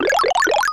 Sound effect from Super Mario RPG: Legend of the Seven Stars
File usage The following page uses this file: Power-Up (sound effect) Transcode status Update transcode status No transcoding required.
SMRPG_SFX_Power_Up.mp3